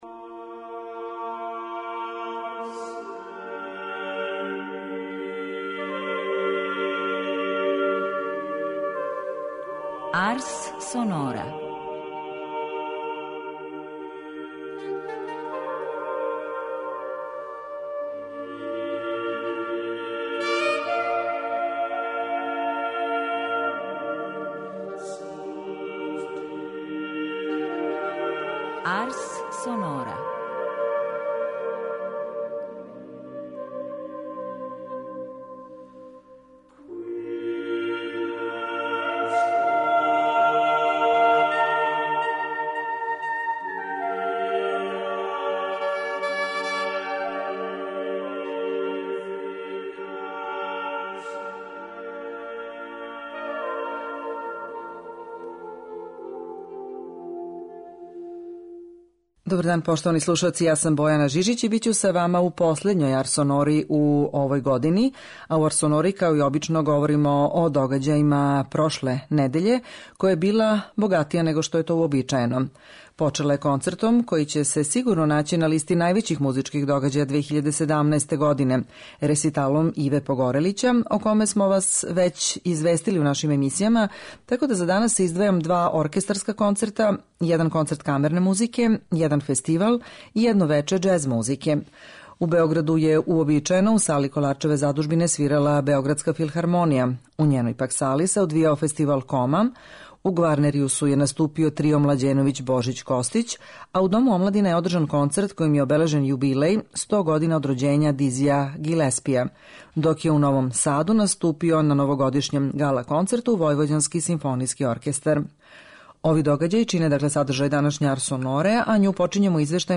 У емисији Арс сонора припремили смо извештаје са концерата два наша оркестарска ансамбла - Београдске филхармоније у Коларчевој задужбини у Београду и Војвођанског симфонијског оркестра у Новом Саду.